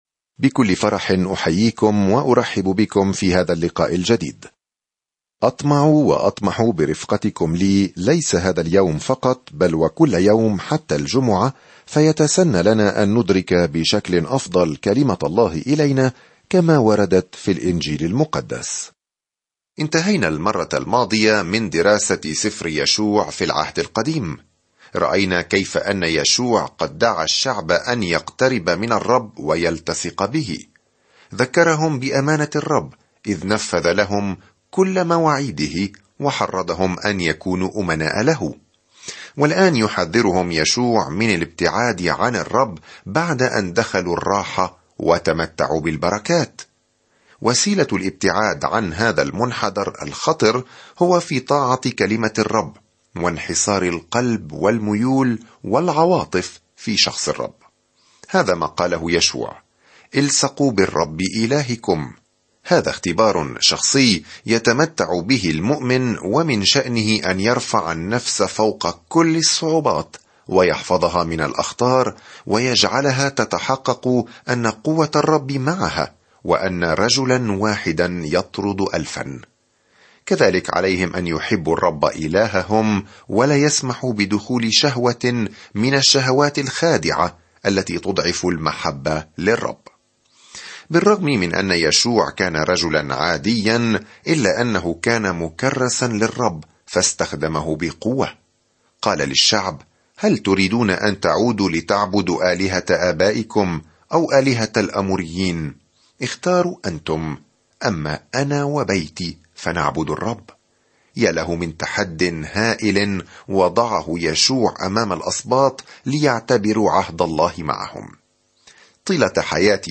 سافر يوميًا عبر يوحنا وأنت تستمع إلى الدراسة الصوتية وتقرأ آيات مختارة من كلمة الله.